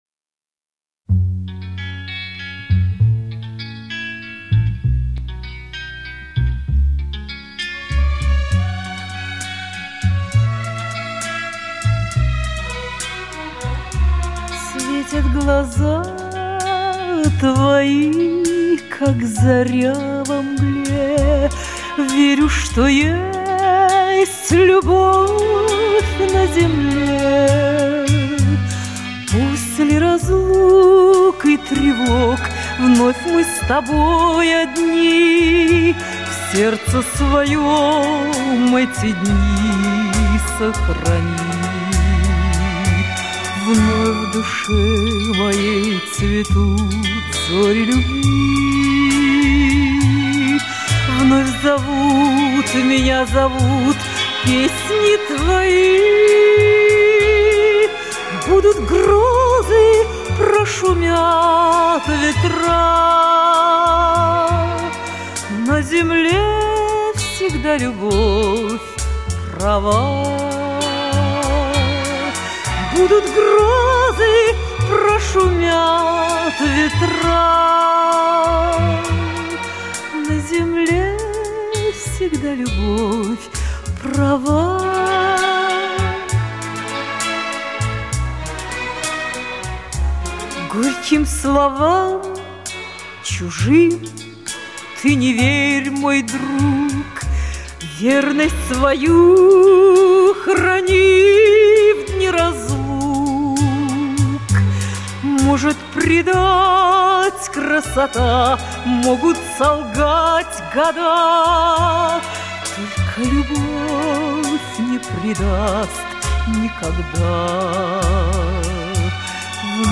Романтическая музыка